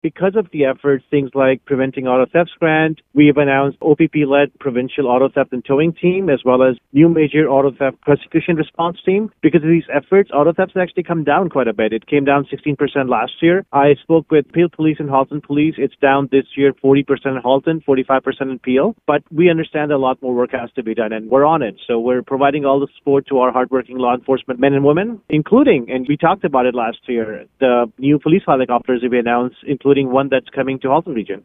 Speaking with Zee Hamid, Milton MPP and Associate Solicitor General for Auto Theft and Bail Reform, he says the numbers continue to be promising as of late, but more needs to be done.